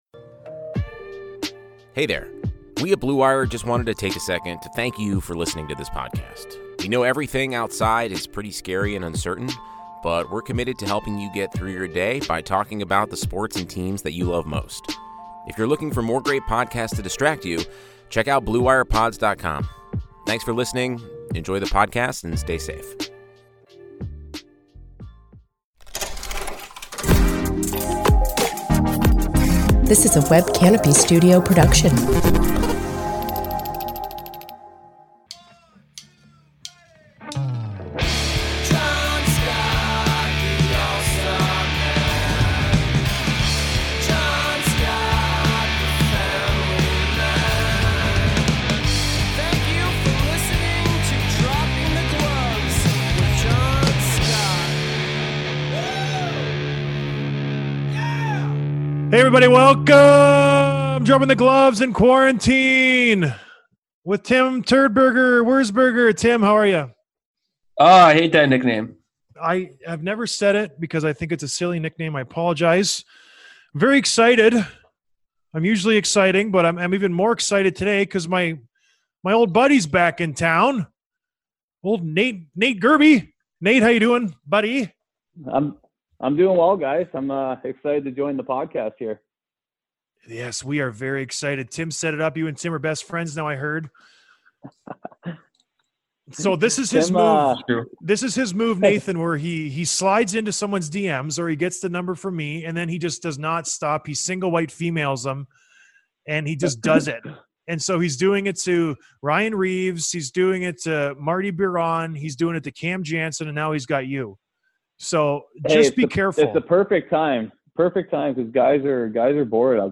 Interview with CBJ Forward Nathan Gerbe